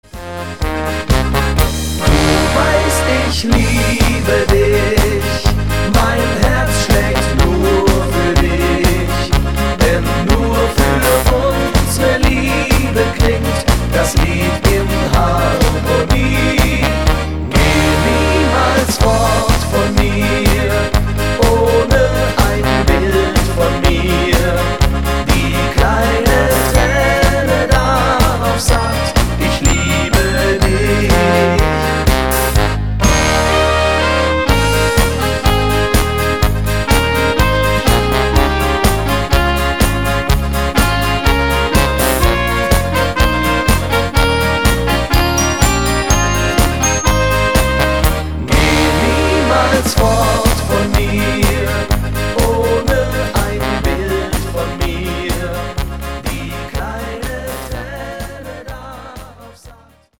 Powermix Version